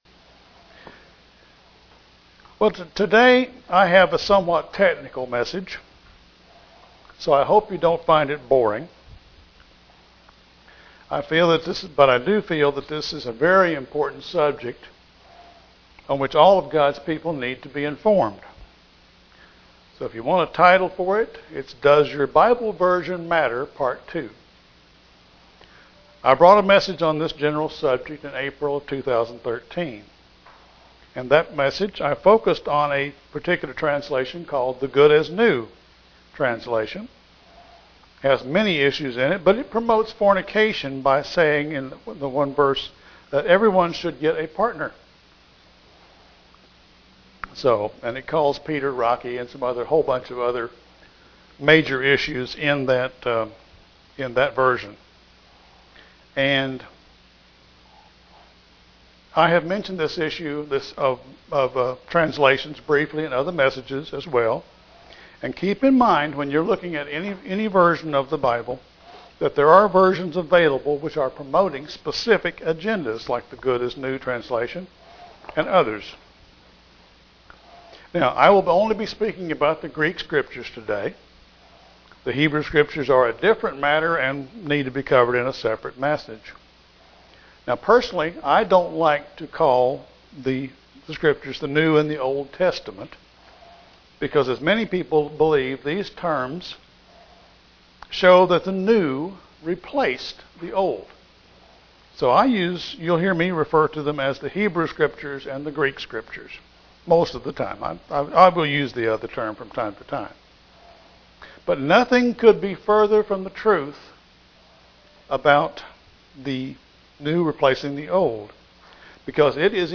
Sermons
Given in Kingsport, TN Knoxville, TN London, KY